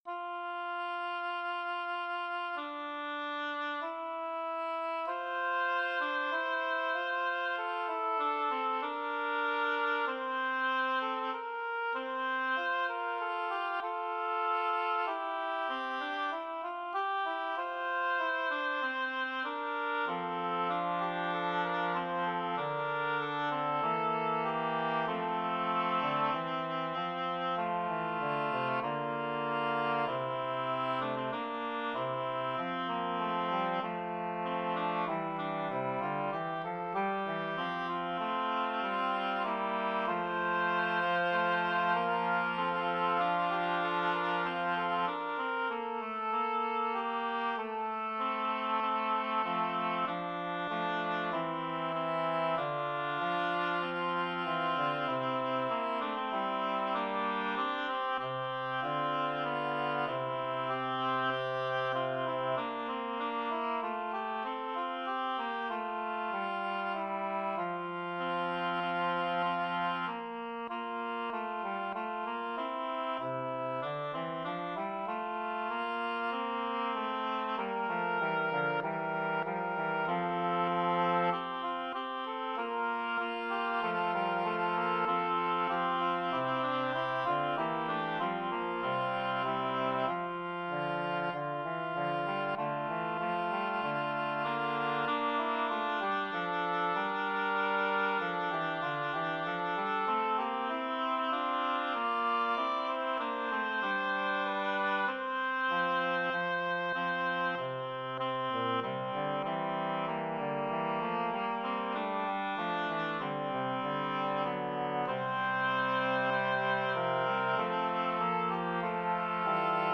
Number of voices: 4vv Voicing: SATB Genre: Sacred, Motet, Responsory for for the Feast of Many Martyrs
Language: Latin Instruments: A cappella